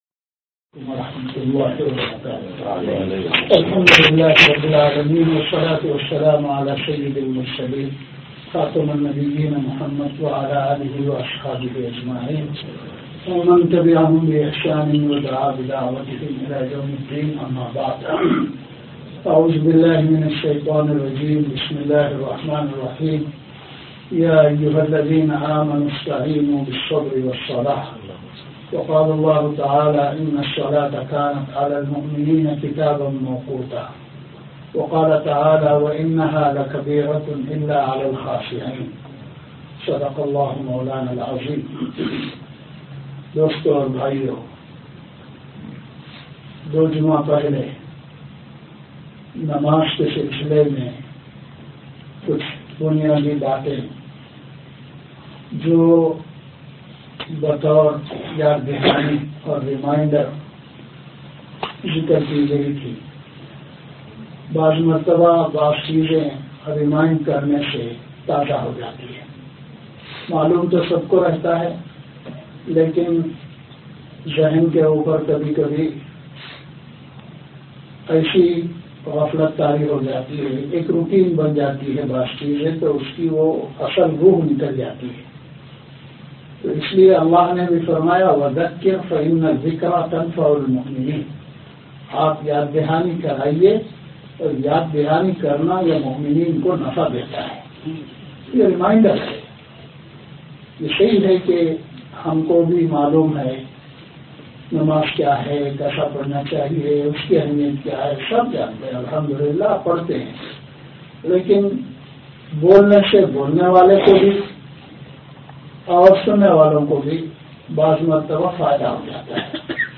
Jum'ah Bayan